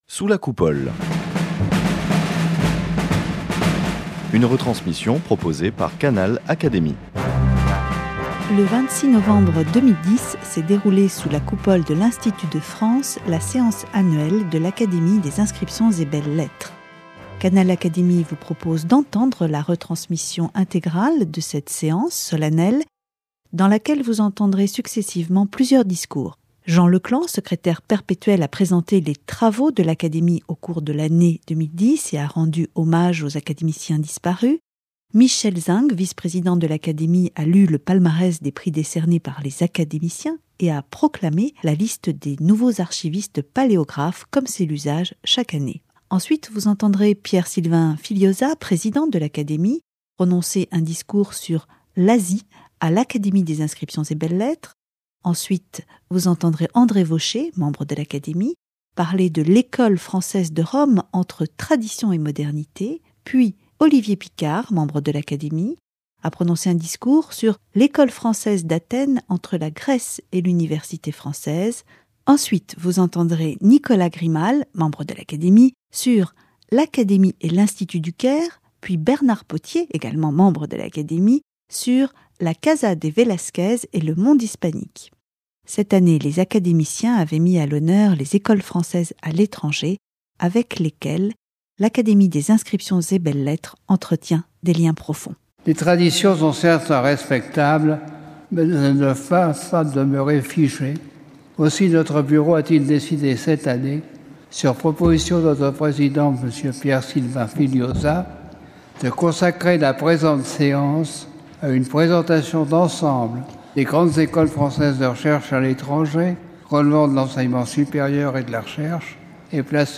Retransmission de la Séance publique annuelle de l’Académie des inscriptions et belles-lettres 2010
Dans cette retransmission proposée par Canal Académie, vous entendrez plusieurs discours.